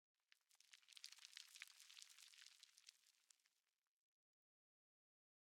1.21.5 / assets / minecraft / sounds / block / sand / sand17.ogg
sand17.ogg